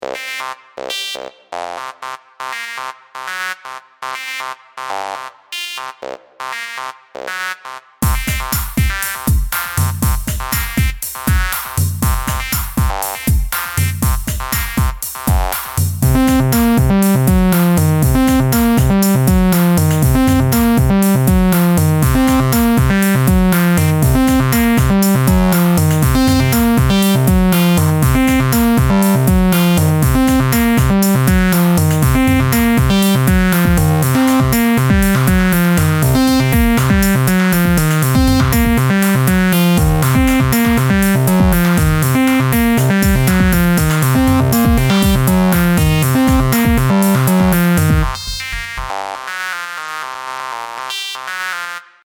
Bucle de Jungle
pieza melodía repetitivo rítmico sintetizador